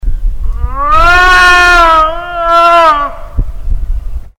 Click here to hear the howl the witness did. This howl was recorded on a different day.